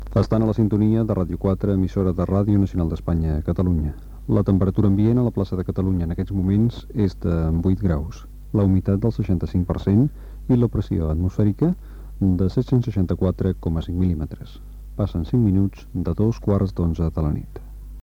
Identificació de l'emissora, estat del temps, hora